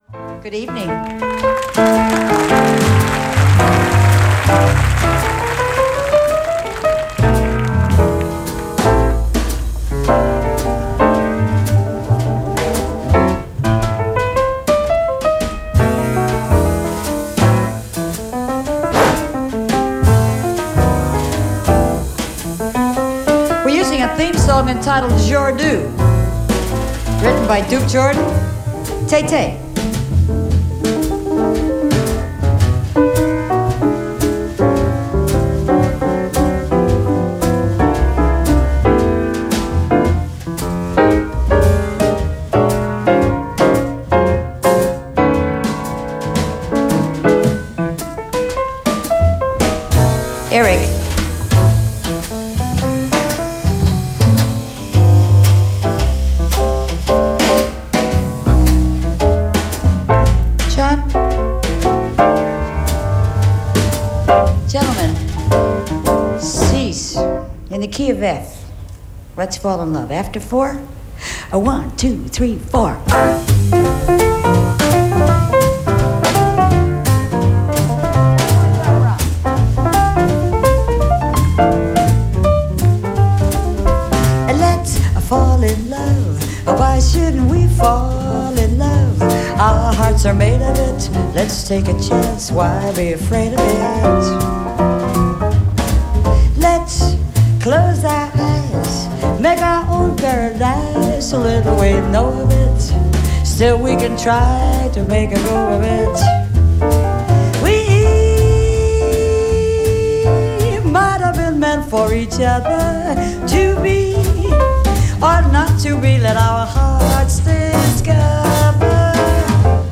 Legends of Jazz in concert.
live at Juan les Pins festival
piano
drums
Jazz singing